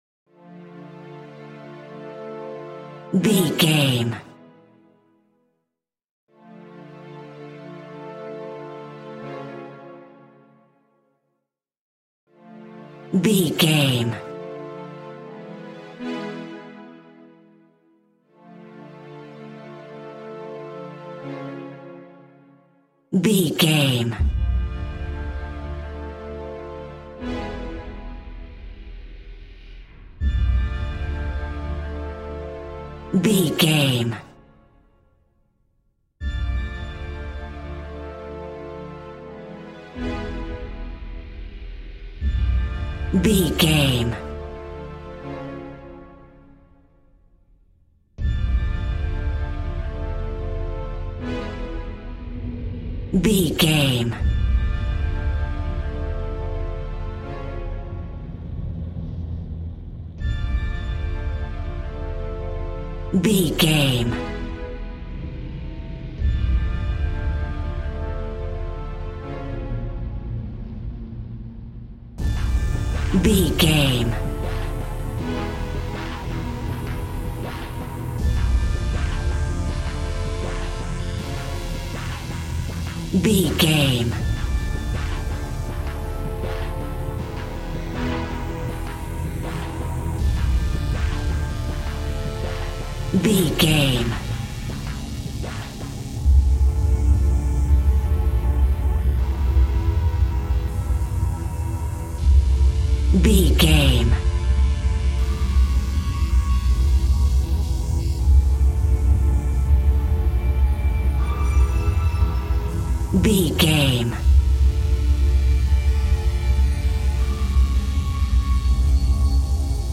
Aeolian/Minor
tension
suspense
piano
synthesiser